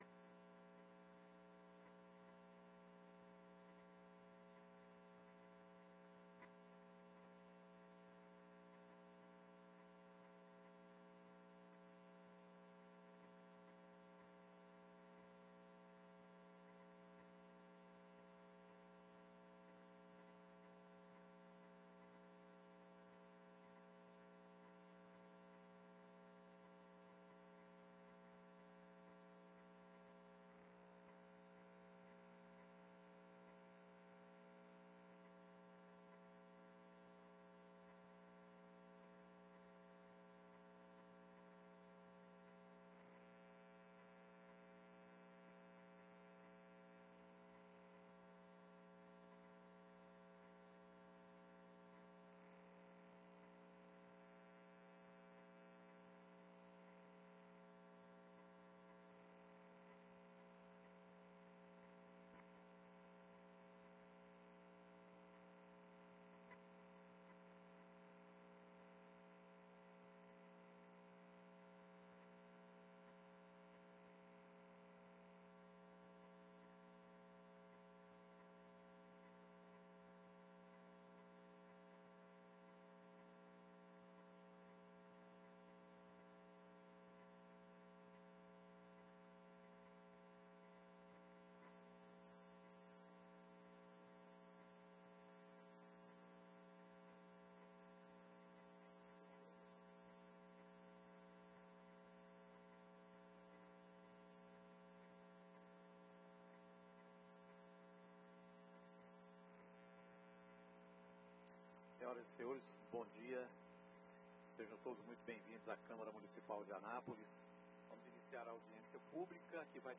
Audiência Publica sobre o sistema de regularização na saúde no estado de goiás, Anápolis e cidades vizinhas 02/03/23.